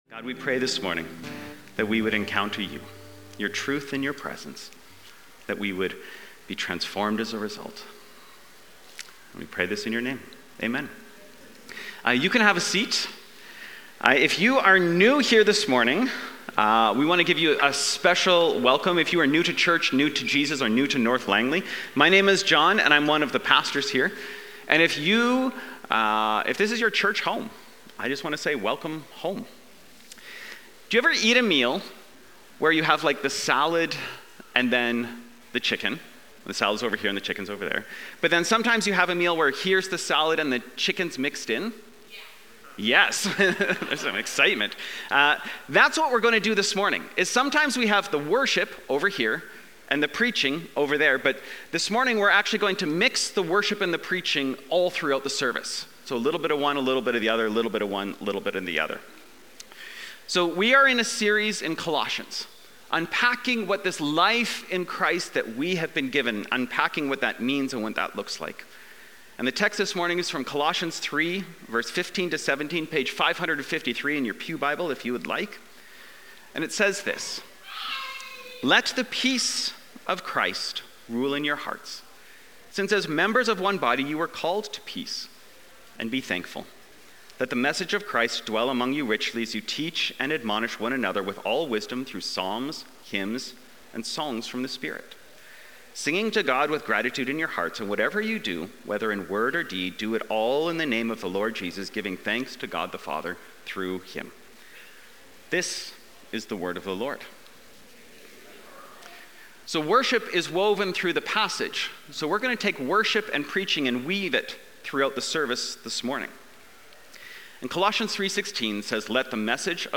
Aldergrove Sermons | North Langley Community Church